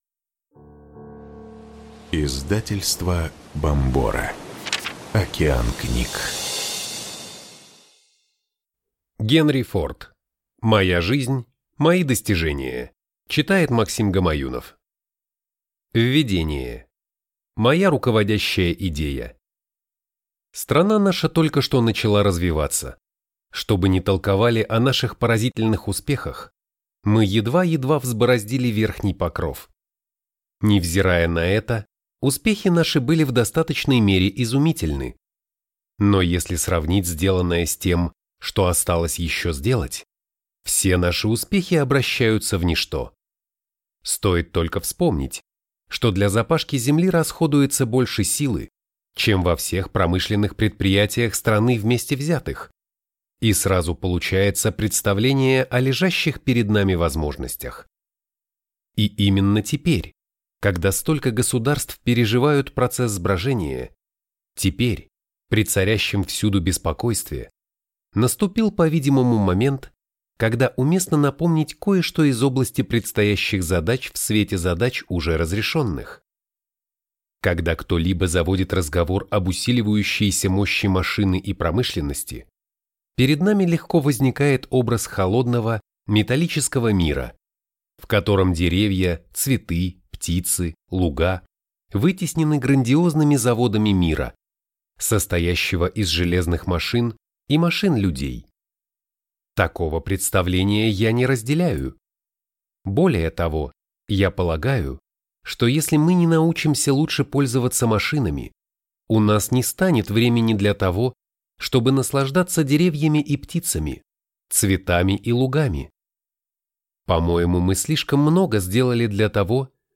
Аудиокнига Генри Форд. Моя жизнь. Мои достижения | Библиотека аудиокниг